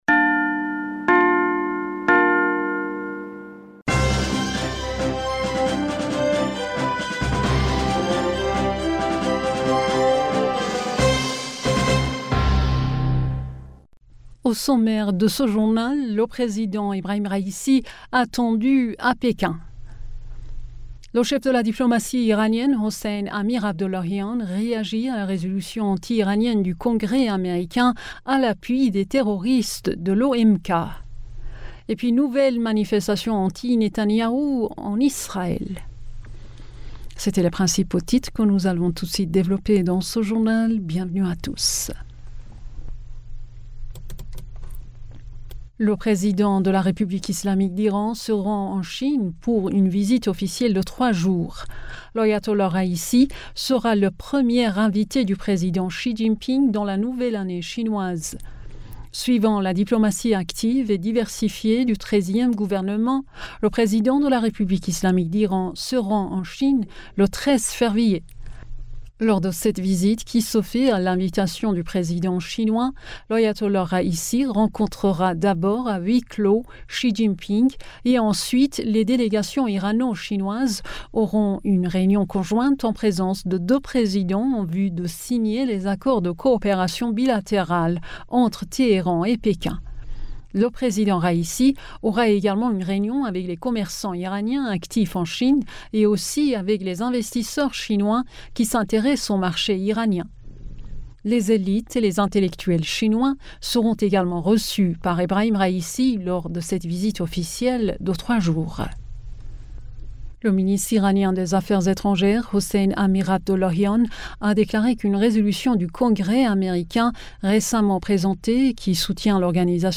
Bulletin d'information du 12 Février